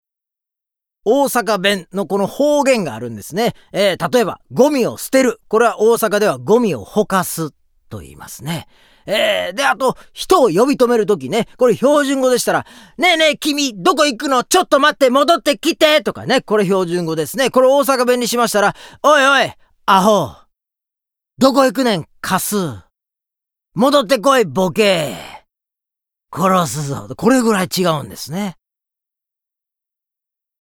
ボイスサンプル ＜現代小噺＞
8_現代小噺.mp3